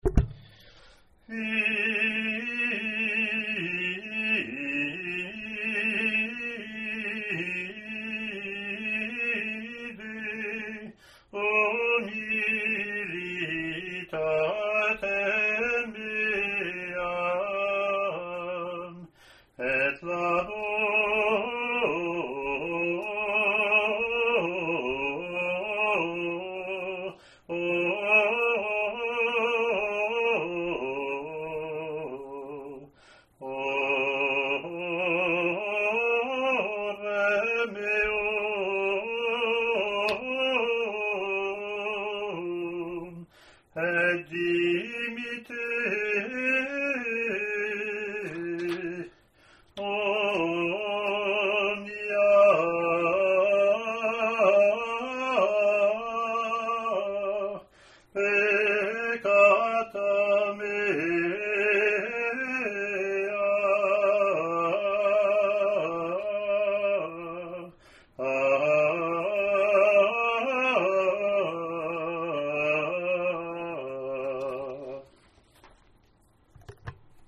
Rehearsal files for Gradual and Tract (Extraordinary Form):
Gradual antiphon